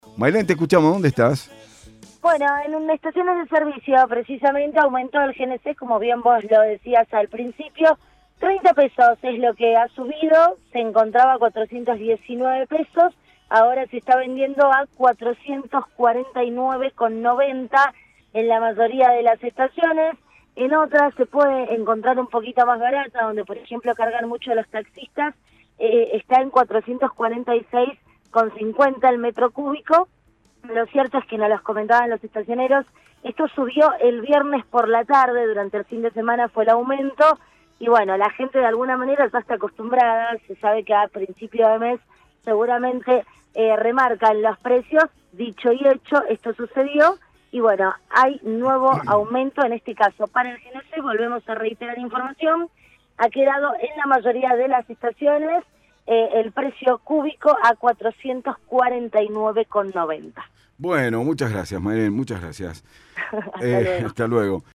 LVDiez - Radio de Cuyo - Móvil de LVDiez -Aumentó el GNC